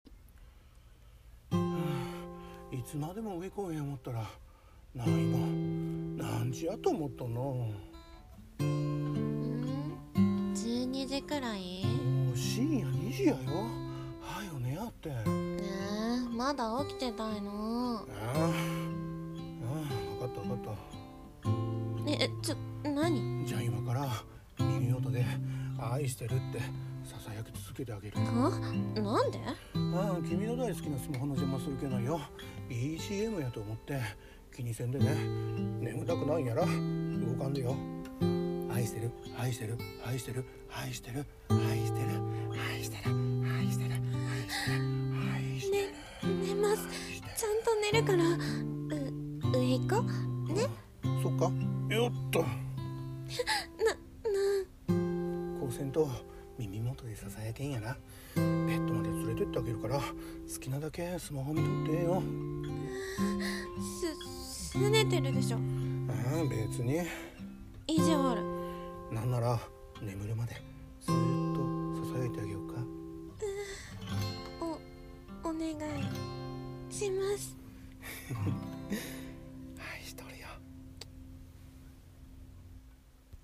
【甘々声劇台本】いつまでたっても眠らない君へ